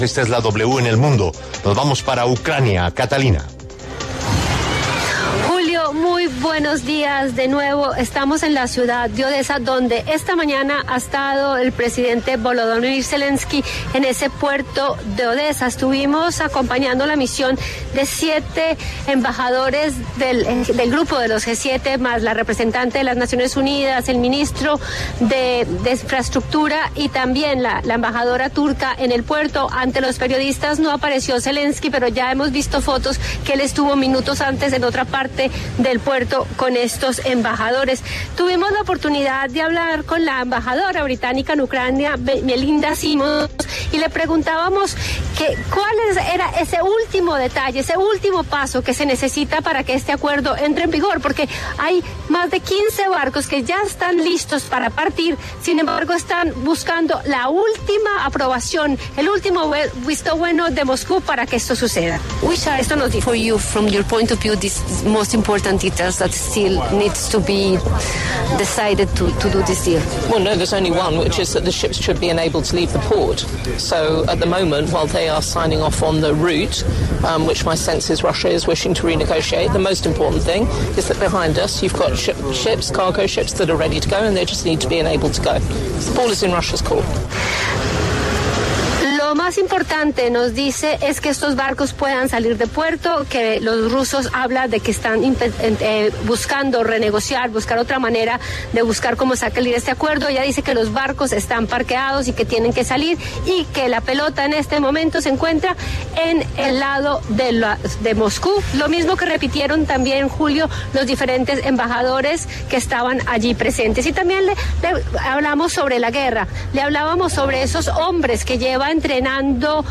Desde Odesa, La W conversó con la embajadora británica en Ucrania, Melinda Simmons, quien indicó que “la pelota está del lado de Moscú”.